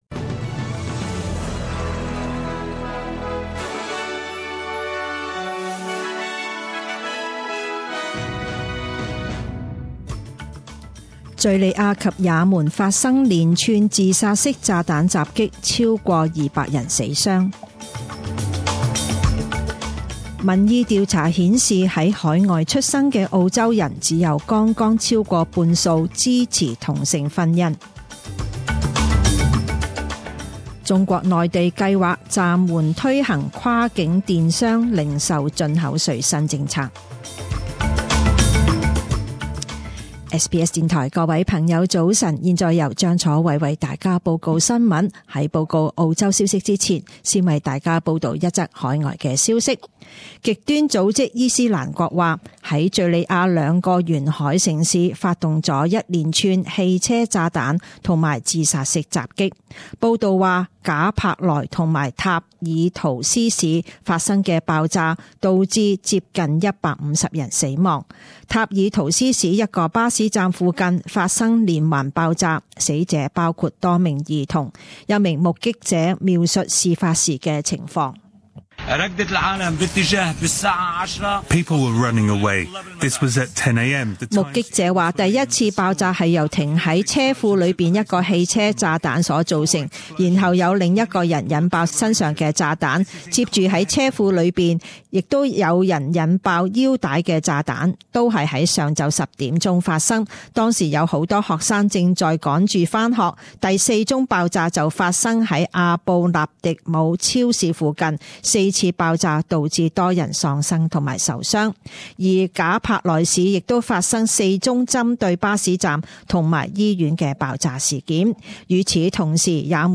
Morning news bulletin